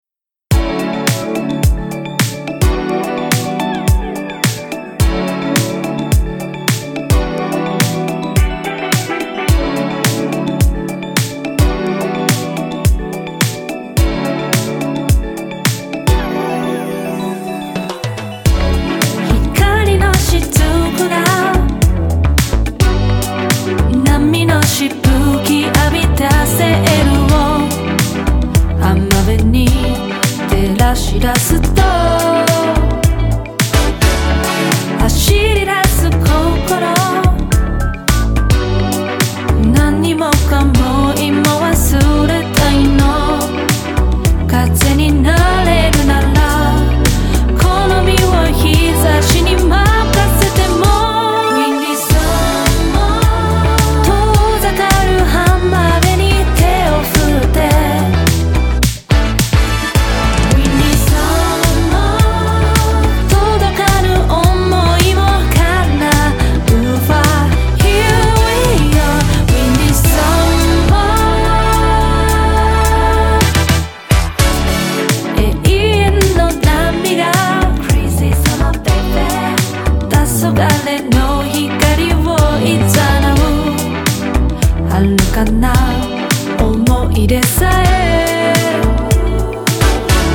CITY POP / AOR
ネオソウルマナーをベースに、オリジナル曲のキラキラ感と爽やかな夏を感じるリリック＆メロディーをクールに表現。